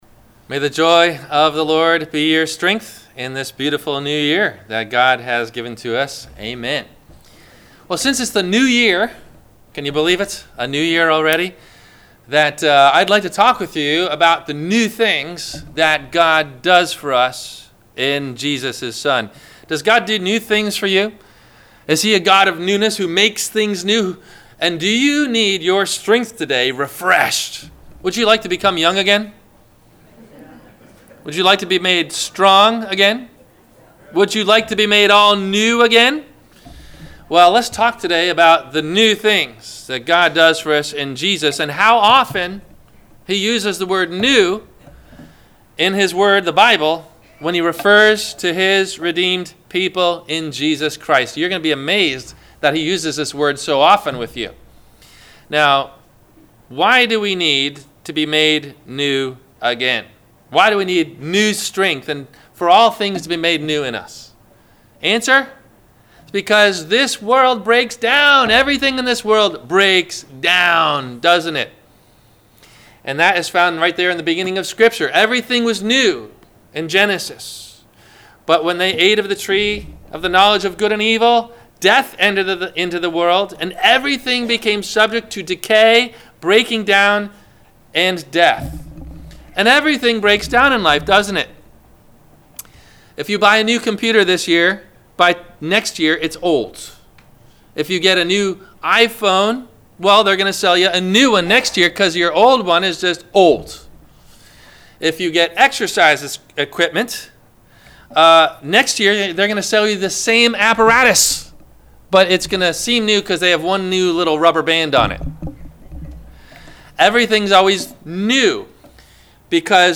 The New Things of God - Sermon - January 06 2019 - Christ Lutheran Cape Canaveral